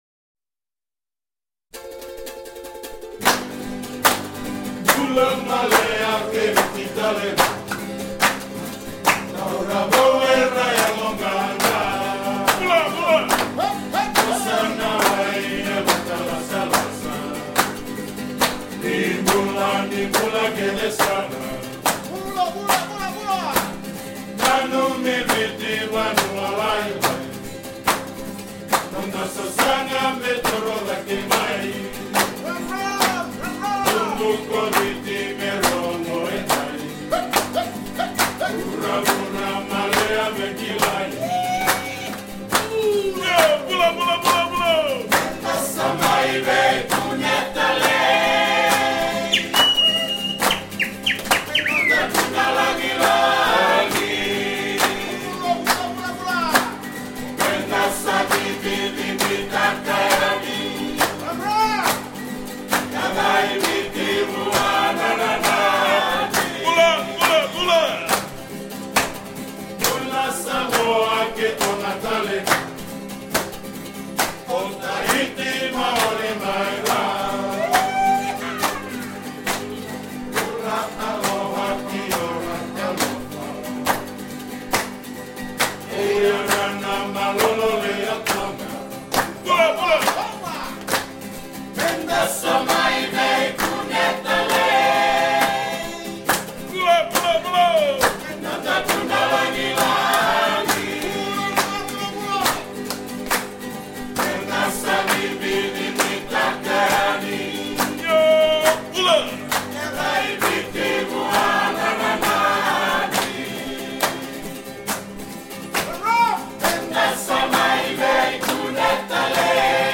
Sehr schön ist auch die Tradition, Gäste mit Gesang willkommen zu heissen bzw. sie bei ihrer Abreise wieder zu verabschieden. Sinngemäss ist der Willkommens-Song „Bula Maleya“ ein sehr fröhlicher Song, während der Abschieds-Song „Isa Lei“ sehr traurig ist und die Abschiede jeweils besonders emotional und schwer macht:
Diese Lieder werden jeweils mehrstimmig im Chor vorgetragen.